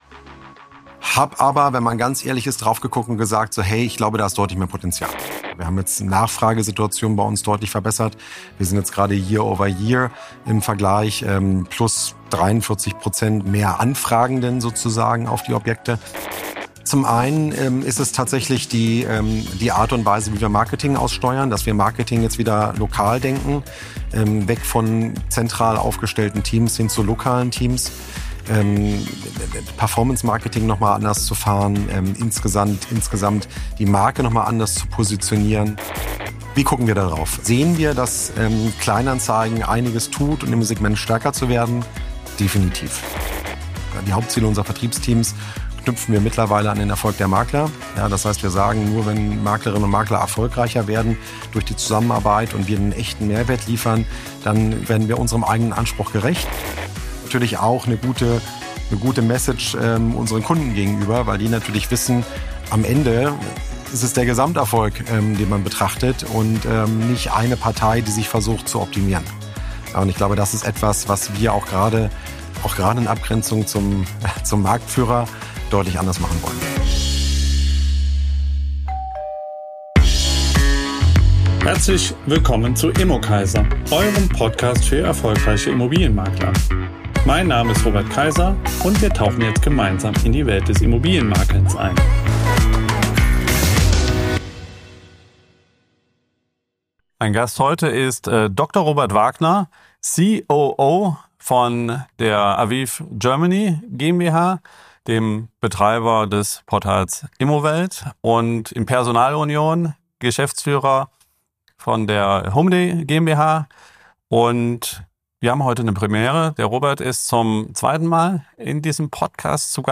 Offen, strategisch, direkt aus Köln – am Tag der Preisverleihung.